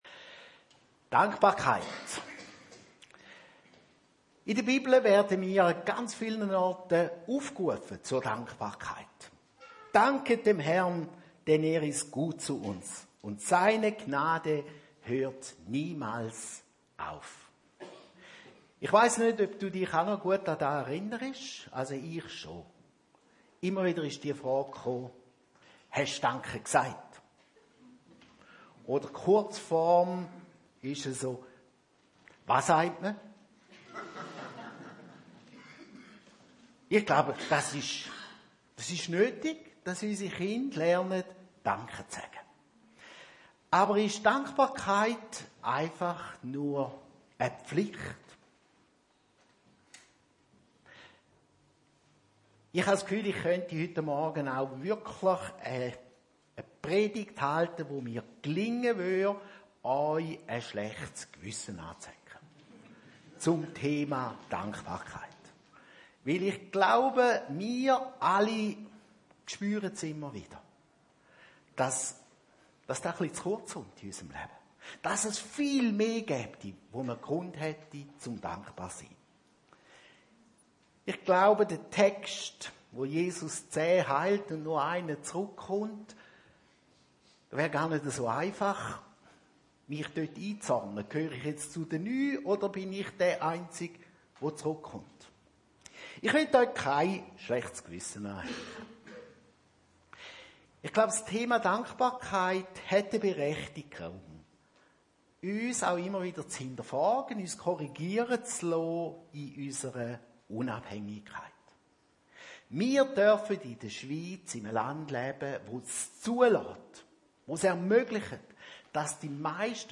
Dank-Gottesdienst – „Dankbarkeit!“